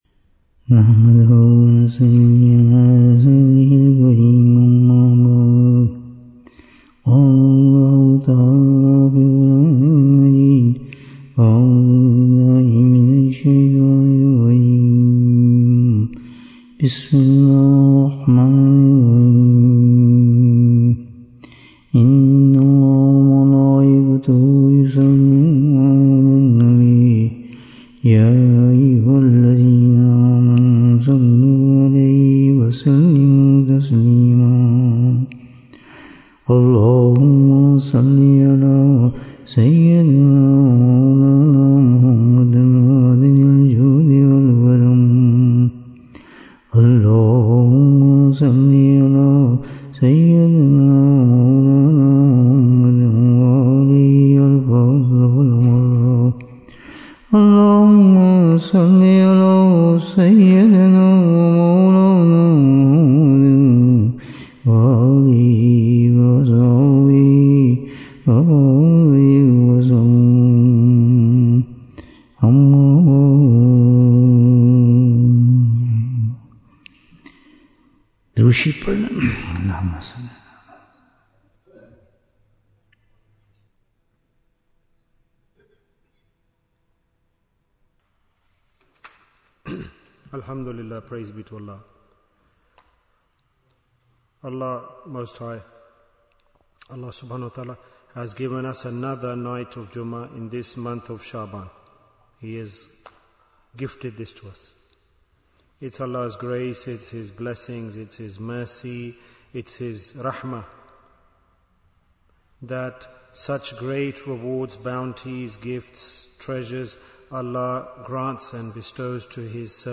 Principles of Preparation for Akhirah Bayan, 90 minutes5th February, 2026